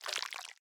Minecraft Version Minecraft Version snapshot Latest Release | Latest Snapshot snapshot / assets / minecraft / sounds / block / honeyblock / slide1.ogg Compare With Compare With Latest Release | Latest Snapshot